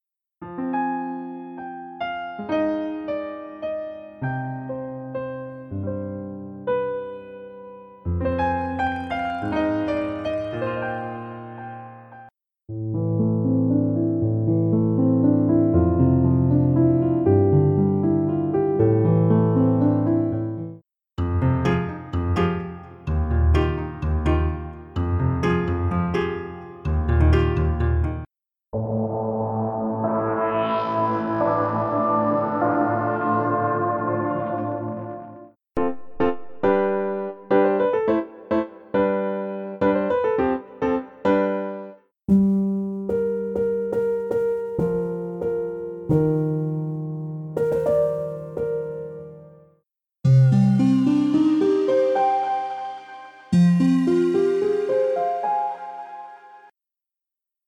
增强型三角钢琴的图书馆收藏了80年代后期施坦威D型钢琴的详细录音，该钢琴被广泛认为是有史以来最伟大的三角钢琴。
增强型三角钢琴将丰富的多采样钢琴声音与最先进的合成相结合，将它们组合成一种平易近人，令人兴奋的软件乐器。
钢琴用乒乓球演奏，用弓，在琴弦之间用纸演奏。我们还更进一步，通过磁带机、踏板、录音室设备等处理这些声音。
解锁您一直想要的鼓舞人心的钢琴声音，从情感的电影配乐到超凡脱俗的视频游戏氛围，从锐利的合成器注入的声音到精致的现代民谣纹理